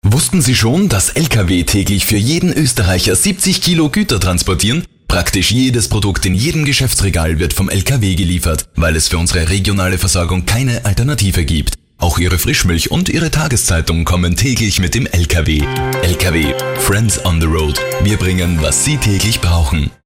30+, unverwechselbar, diverse Comic-Stimmen, von jugendlich schrill über sportlich hektisch bis kompetent seriös
Sprechprobe: Werbung (Muttersprache):